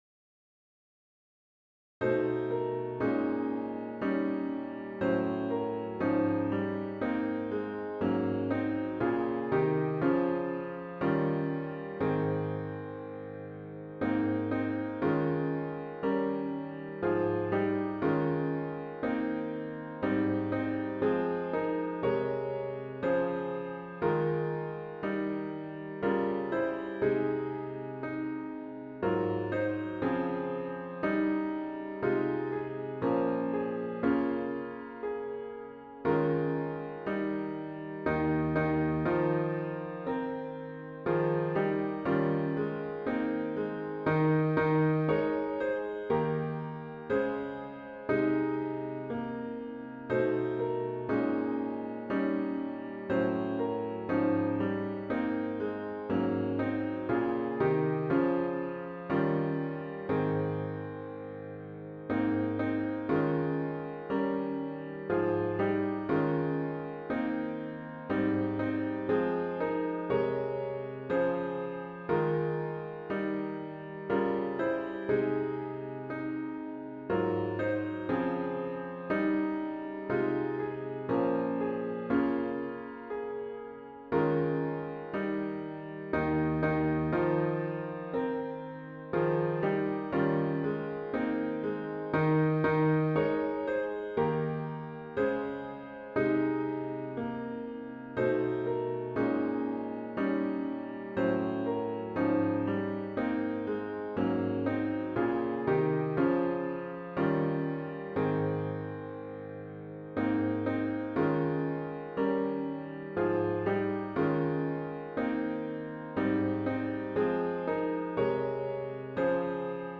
*CLOSING HYMN “Womb of Life and Source of Being” GtG 3  Womb of Life, Text by Ruth Duck, 1986, 1990.